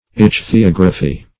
Search Result for " ichthyography" : The Collaborative International Dictionary of English v.0.48: Ichthyography \Ich`thy*og"ra*phy\, n. [Gr.